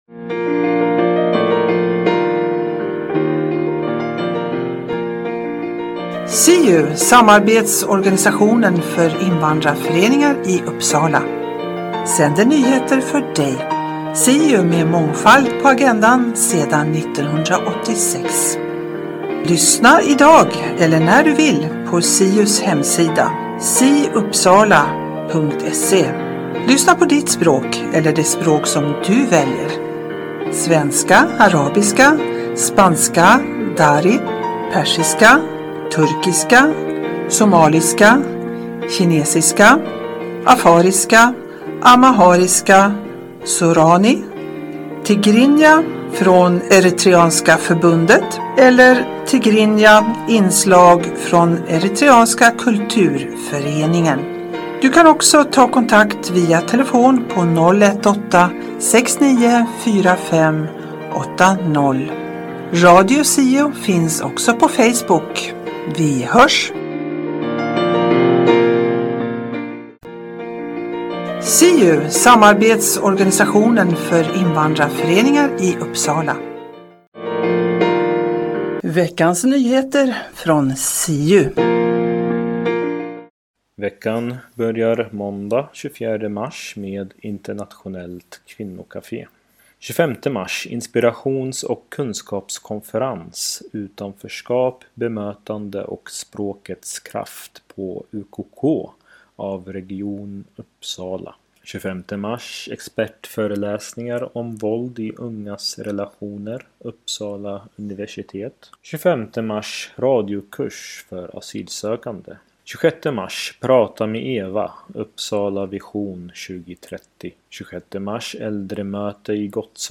Siu-programmet på svenska innehåller SIU:s nyheter, Nyheter Uppsala och Riksnyheter. Berika din fritid med information och musik.